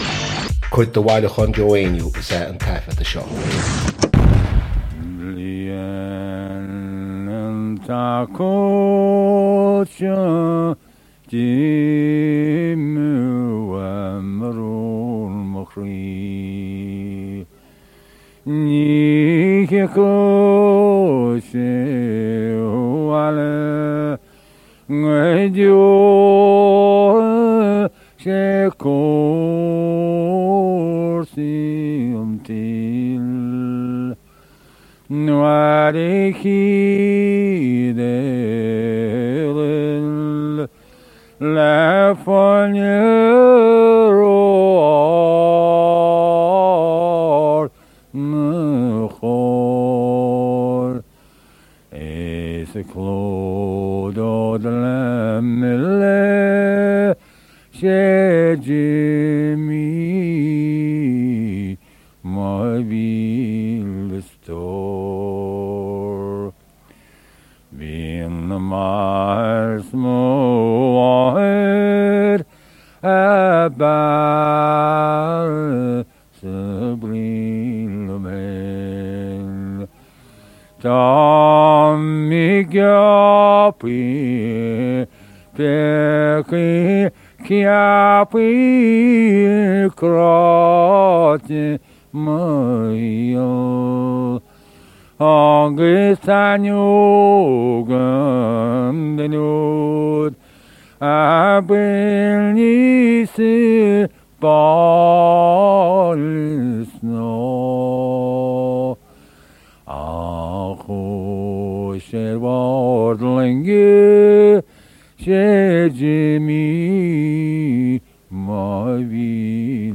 • Catagóir (Category): song.
• Ainm an té a thug (Name of Informant): Joe Heaney.
• Ocáid an taifeadta (Recording Occasion): studio session.
1. For some reason, Joe sings the second verse of this song twice.
This is not a Conamara song but a Munster one — as the language clearly indicates.
Recorded for John Cage’s Roaratorio.